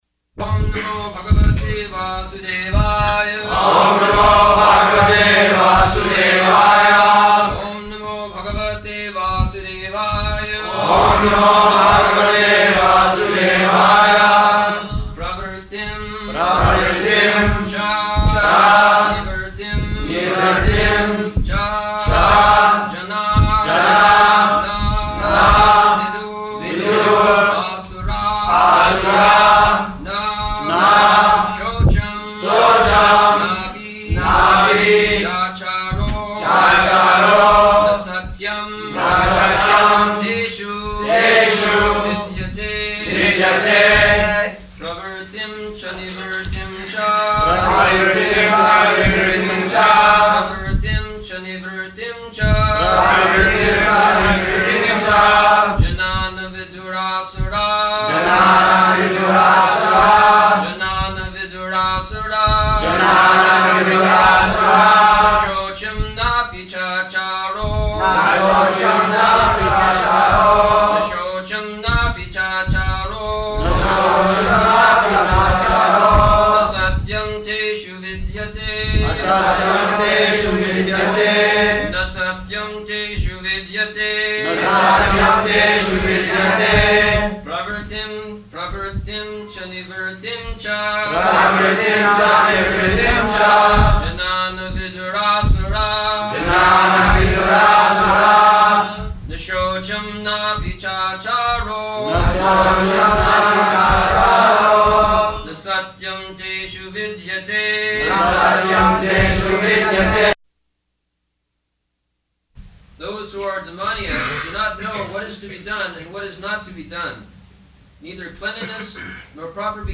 February 3rd 1975 Location: Honolulu Audio file
[devotees repeat] [leads chanting of verse, etc.]